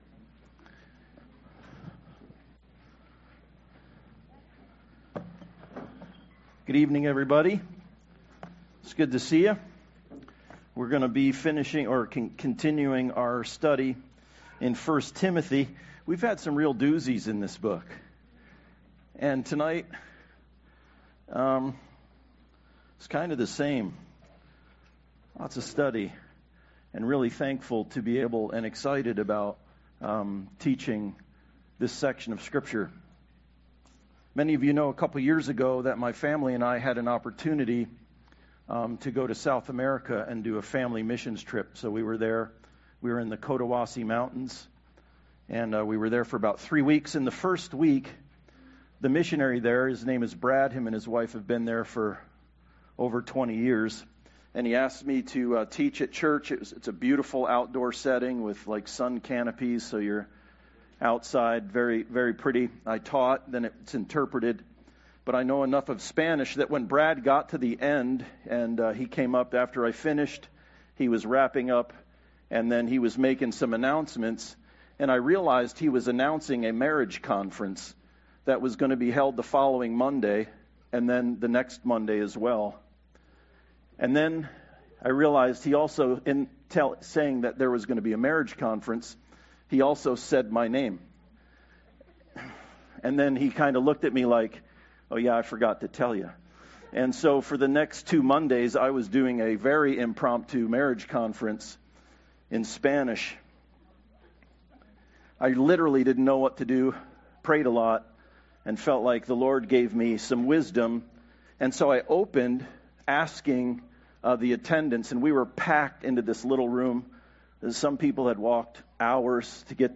1 Timothy 3:8-6 Service Type: Sunday Service Defining the Role of Deacon